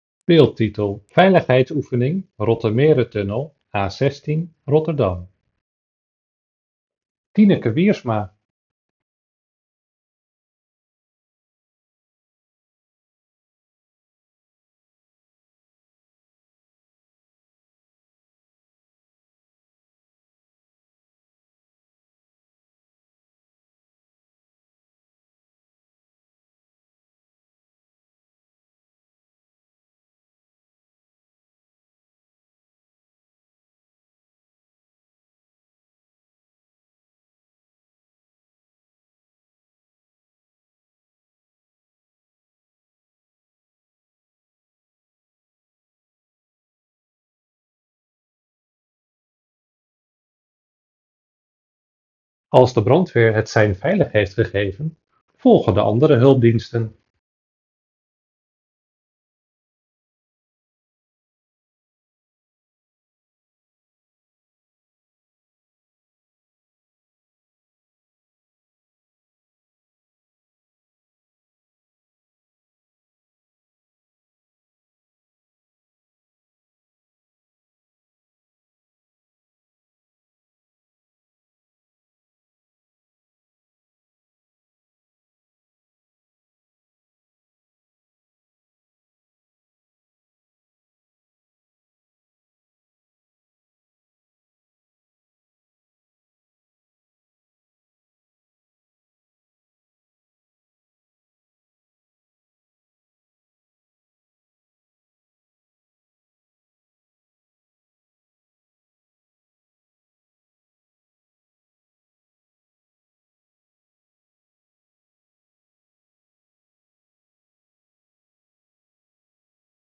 Oefening met hulpdiensten
Hulpdiensten oefenden onder andere met een levensechte brand.